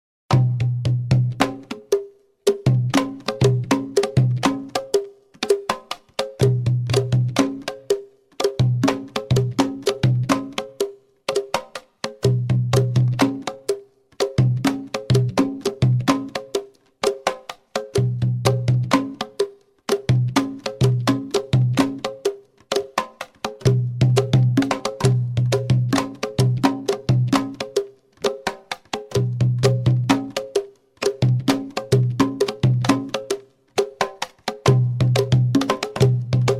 ODDÚA (oro seco)